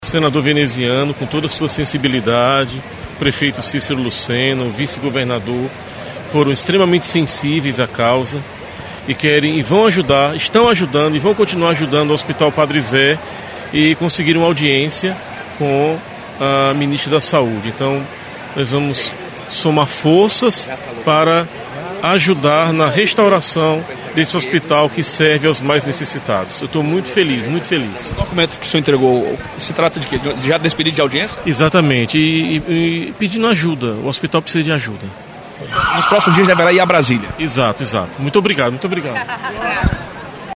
Os comentários do religioso foram registrados pelo programa Correio Debate, da 98 FM, de João Pessoa, nesta quinta-feira (23/11).